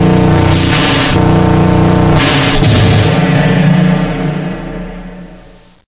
attach_swell.ogg